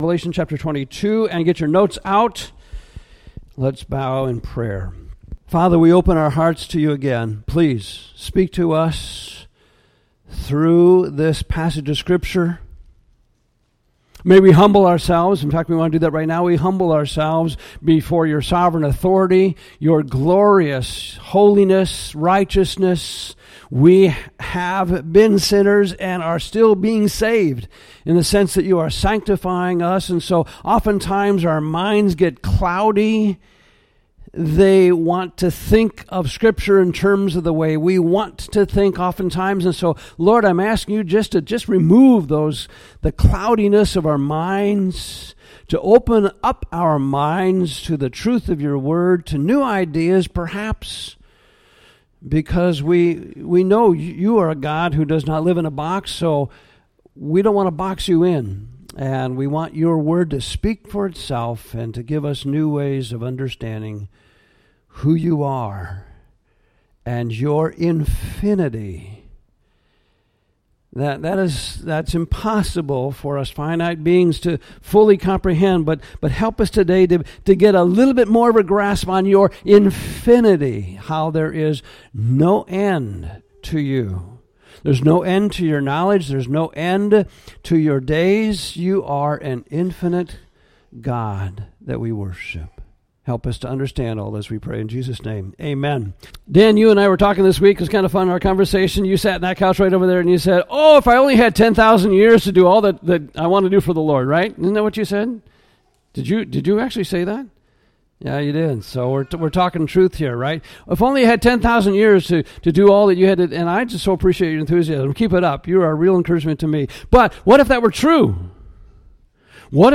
Cornerstone Church The Revelation of Jesus Christ Sermon Series